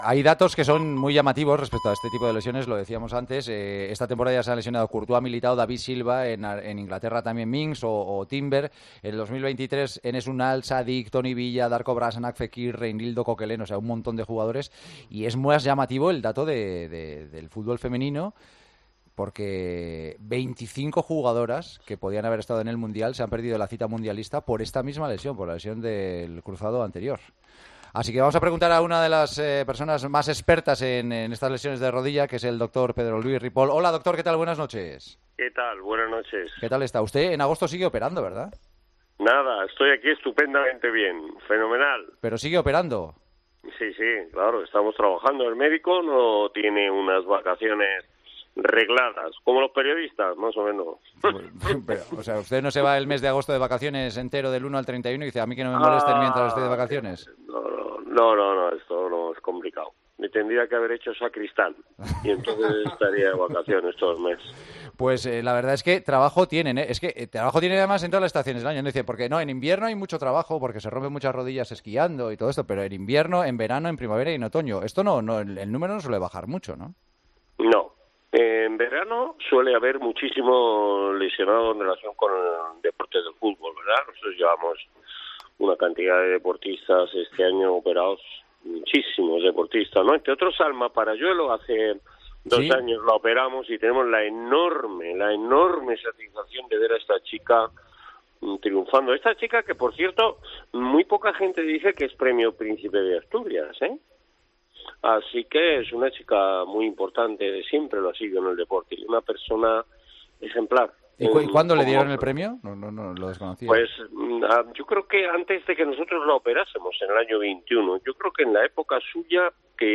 AUDIO: Hablamos con el doctor sobre las operaciones de ligamentos a Courtois y Militao y la cantidad de lesiones de cruzado en los últimos años.